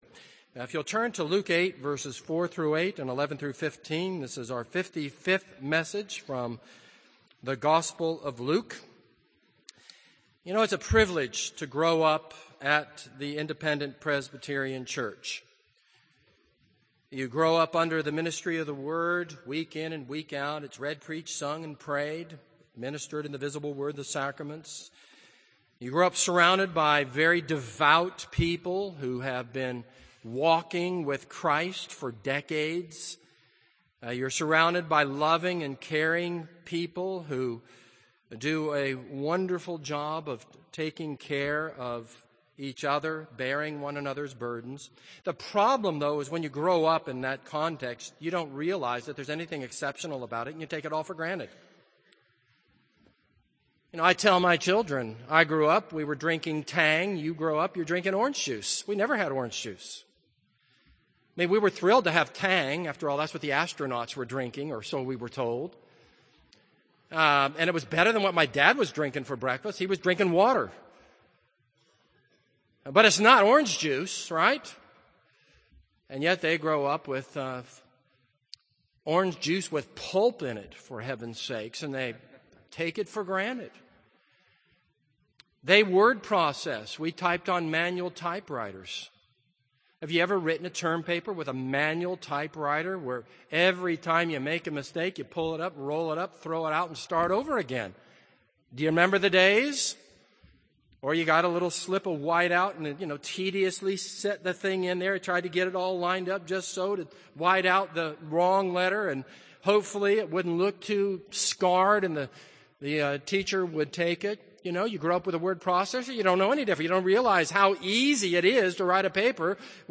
This is a sermon on Luke 8:4-8 and Luke 8:11-15.